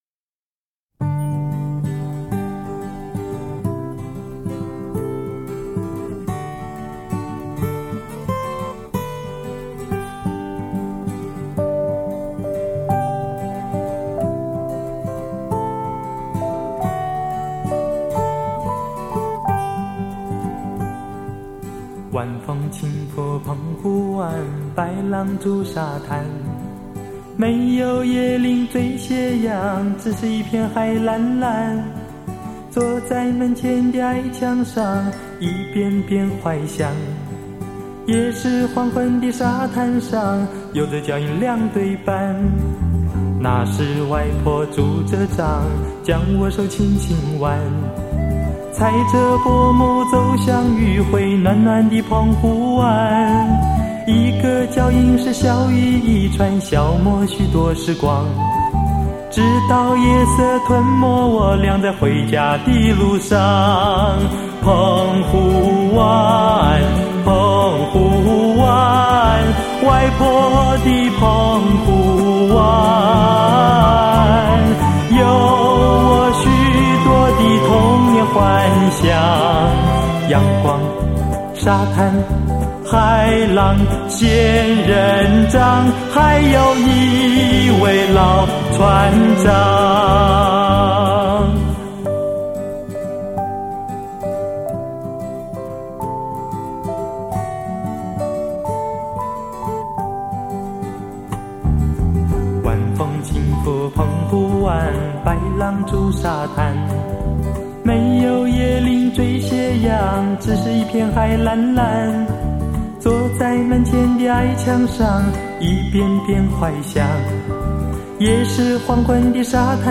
震惊台湾全省民谣歌手
而他的歌声，听起来，都让人觉得古典味道十足，极具优雅的回味感。